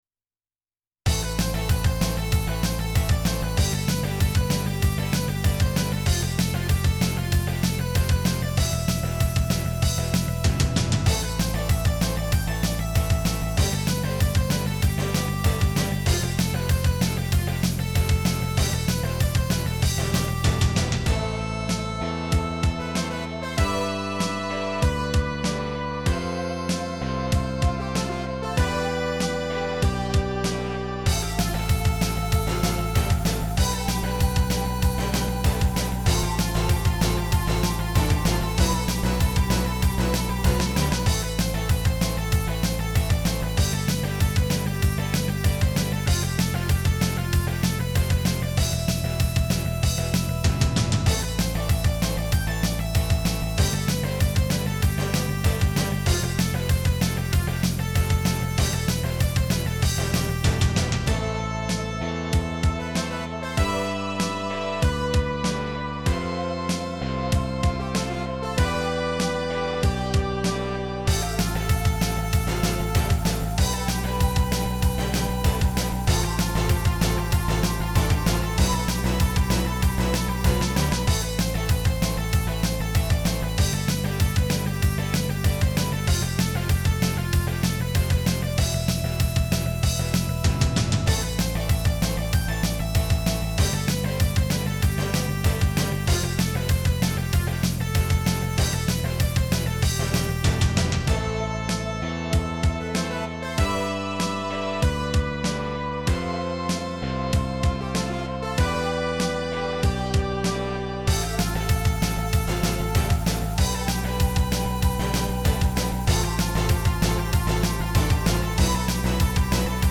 music (MIDI to MP3)